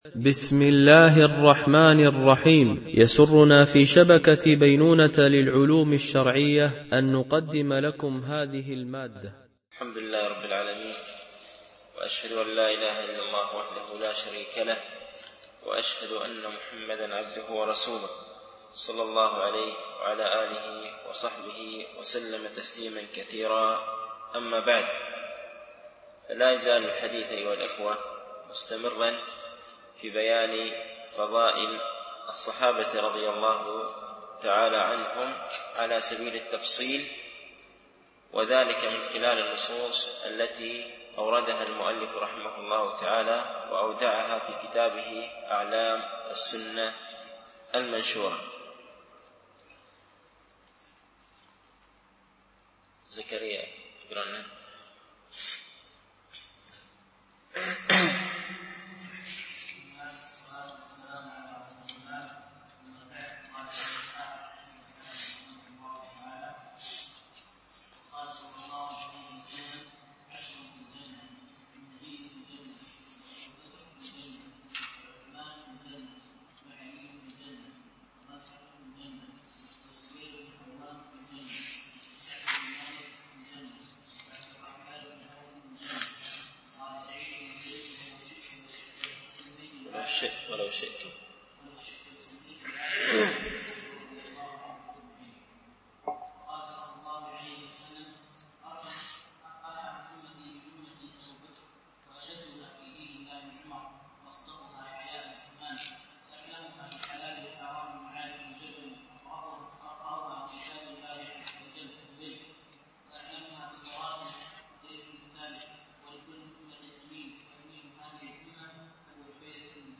) الألبوم: شبكة بينونة للعلوم الشرعية التتبع: 147 المدة: 25:27 دقائق (5.86 م.بايت) التنسيق: MP3 Mono 22kHz 32Kbps (CBR)